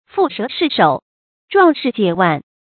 注音：ㄈㄨˋ ㄕㄜˊ ㄕㄧˋ ㄕㄡˇ ，ㄓㄨㄤˋ ㄕㄧˋ ㄐㄧㄝ ˇ ㄨㄢˋ